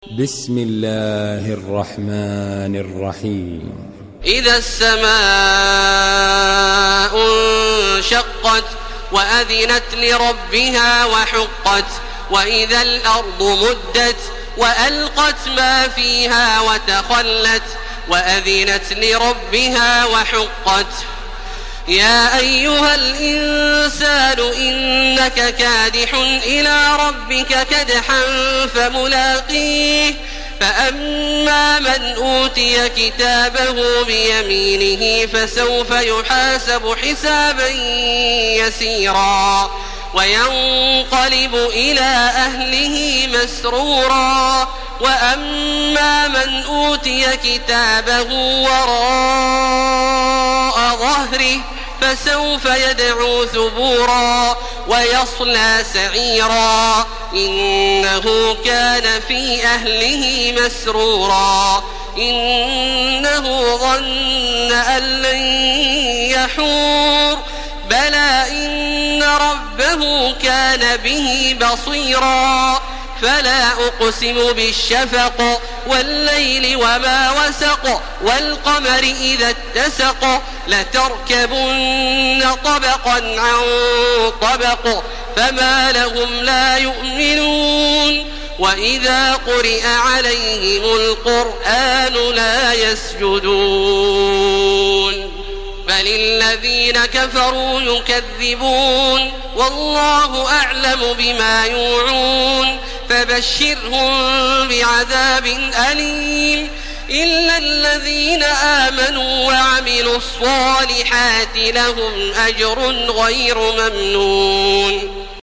تراويح الحرم المكي 1431
مرتل حفص عن عاصم